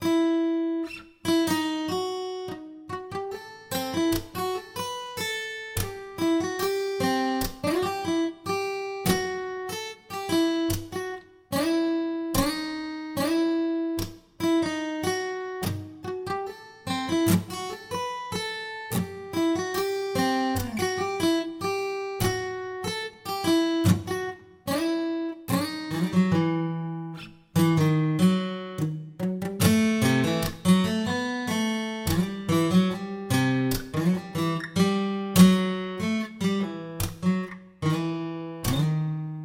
描述：以146 bpm的速度覆盖，用于制作rap混音或电子混音
Tag: 146 bpm Trap Loops Guitar Acoustic Loops 6.64 MB wav Key : G